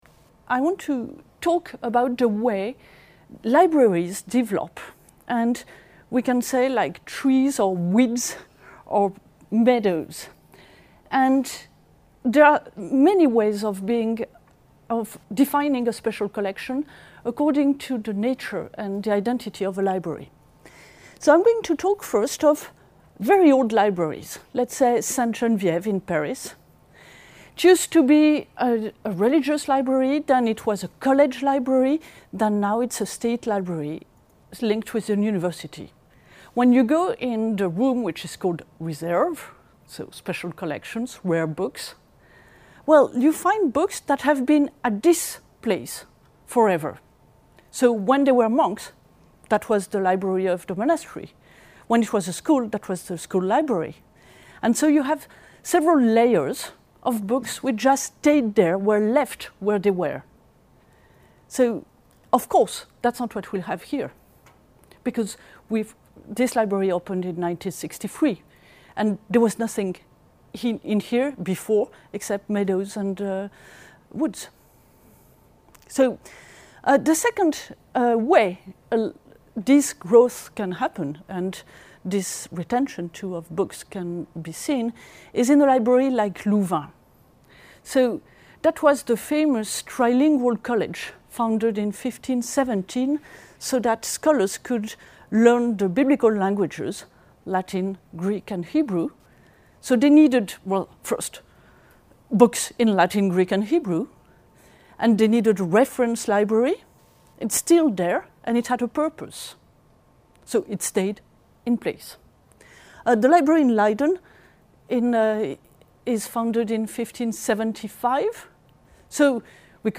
This lecture gives a “guided tour” of a selection of mini-collections within Special Collections: history of medicine, Bibles, discovery of the Americas and Amer-Indian religions, and Aldine editions of modern dictionaries.